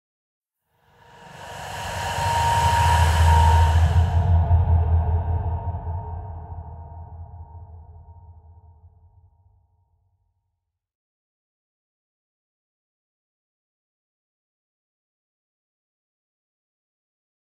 دانلود صدای روح 3 از ساعد نیوز با لینک مستقیم و کیفیت بالا
جلوه های صوتی
برچسب: دانلود آهنگ های افکت صوتی انسان و موجودات زنده دانلود آلبوم صدای ترسناک روح از افکت صوتی انسان و موجودات زنده